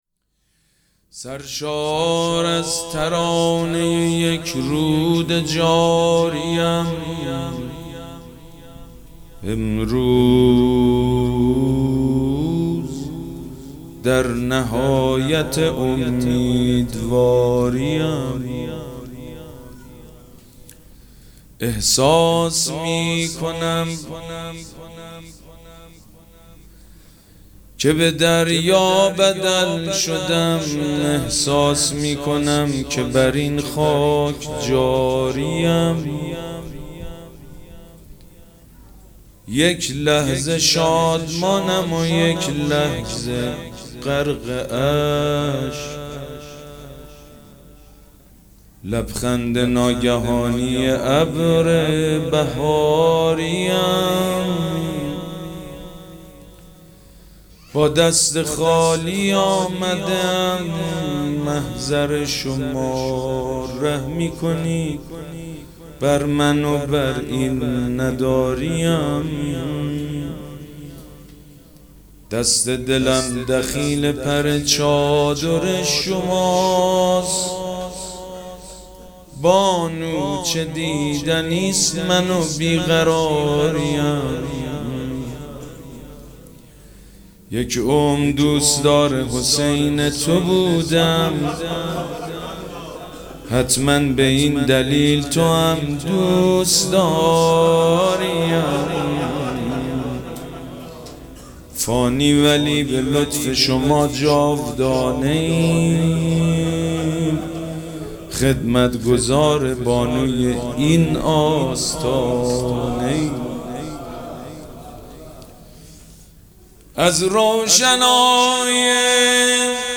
مراسم جشن ولادت حضرت زینب سلام‌الله‌علیها
شعر خوانی
مداح